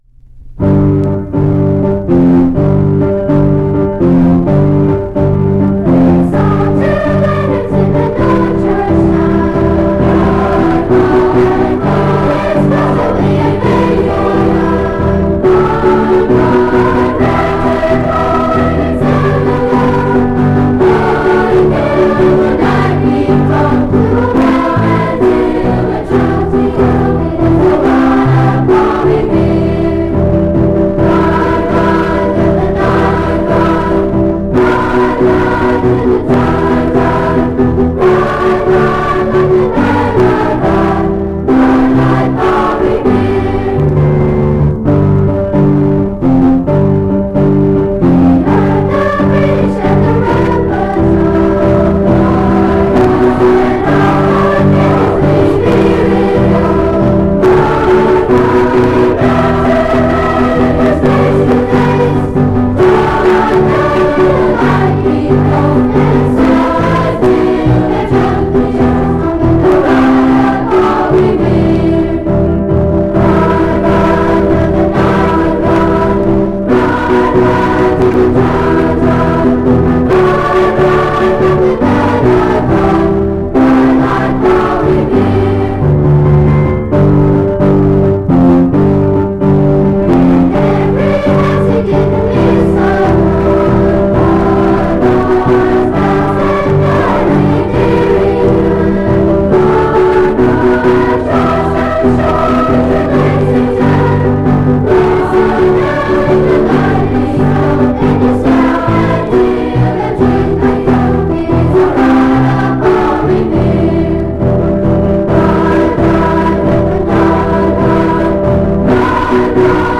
G.W. Carver School 7th Grade Chorus